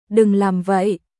Đừng làm vậyDon’t do thatそれはやめてドゥン ラム ヴァイ